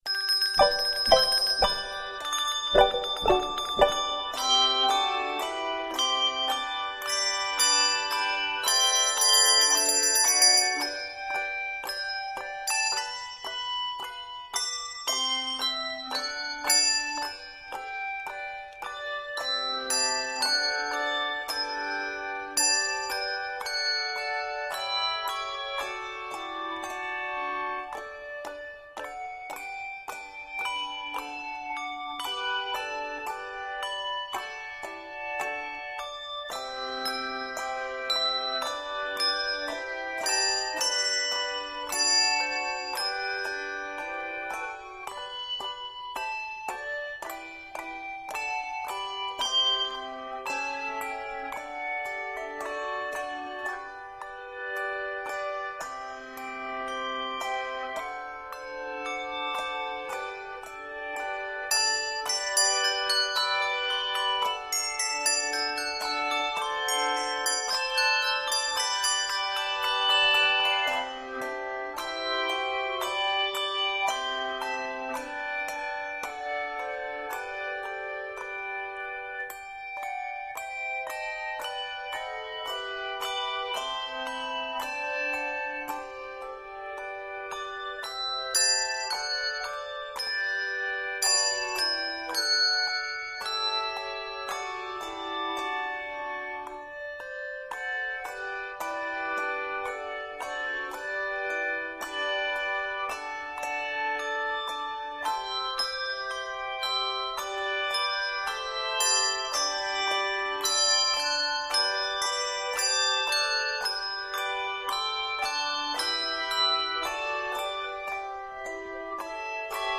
It is scored in G Major.